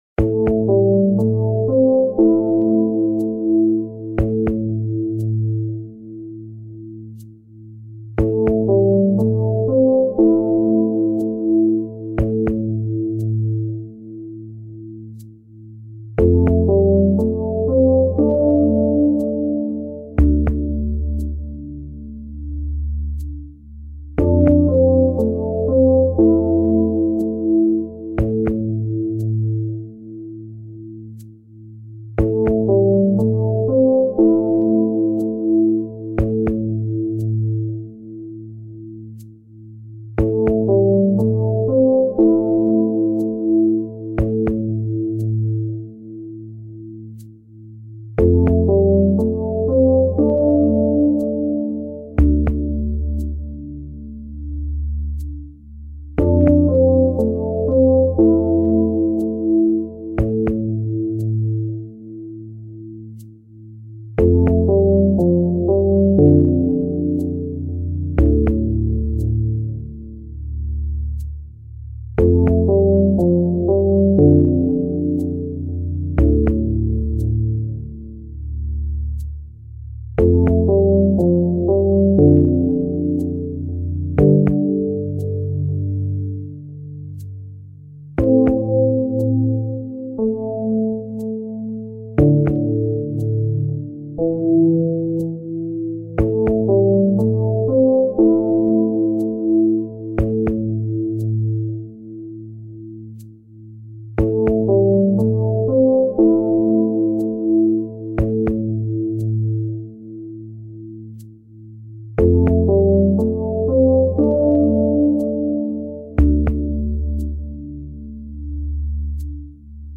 Kostenlos:  Hypnose-Entspannungsmusik mp3 download für die private Nutzung und Praxis-Kunden.
Hypnose-Musik - Tor zur Trance.mp3